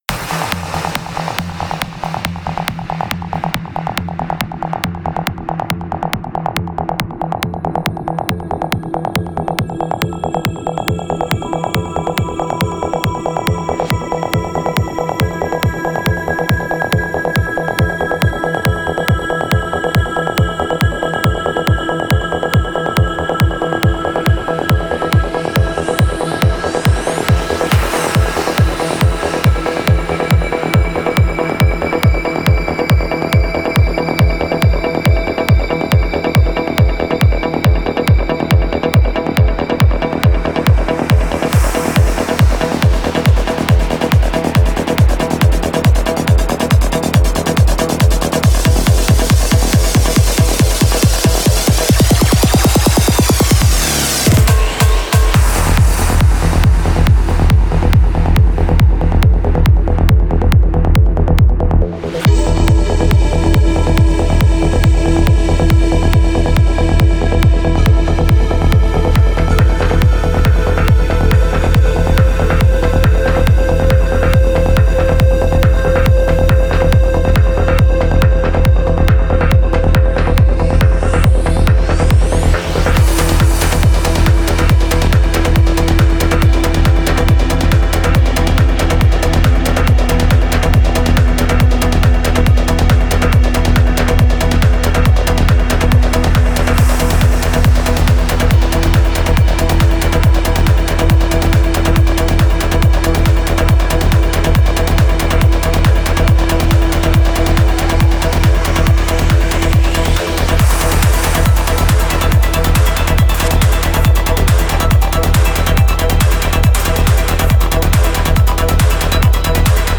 Файл в обменнике2 Myзыкa->Psy-trance, Full-on
Стиль: Tance / Psy Trance